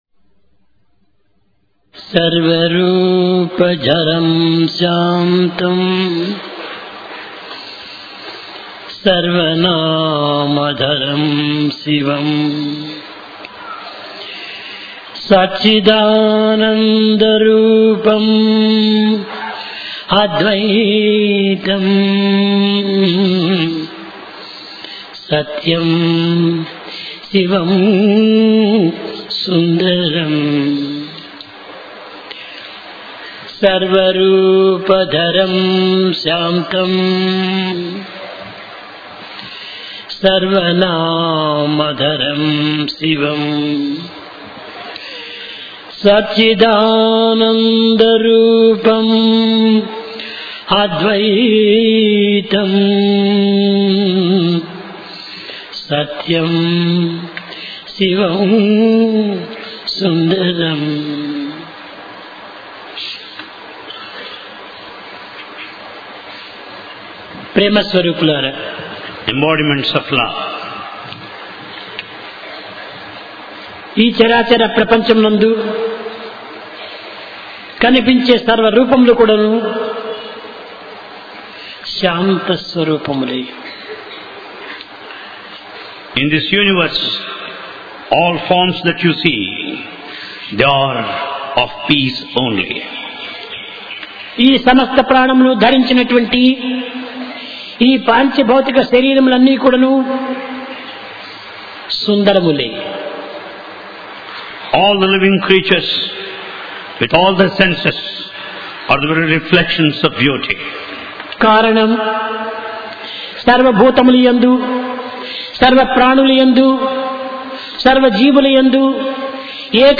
Occasion: Divine Discourse Place: Prashanti Nilayam